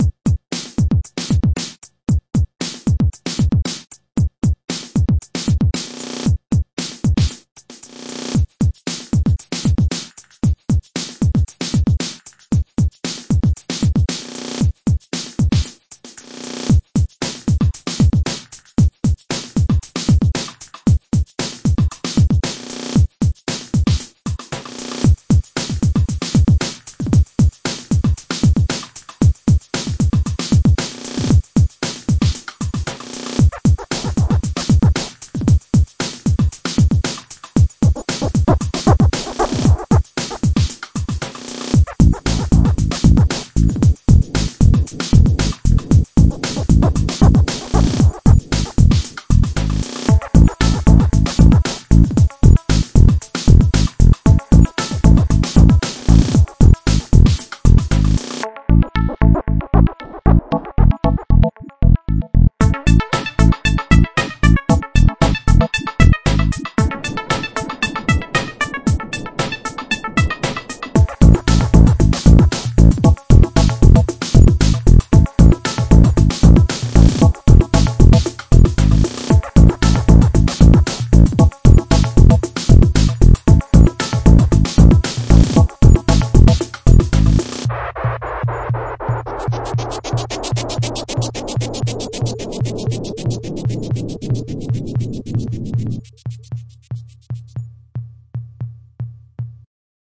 循环播放 115 bpm
Tag: 背景音 白噪声 声景 atmophere 大气 气氛 环境 背景 字段 115 氛围 一般噪声 记录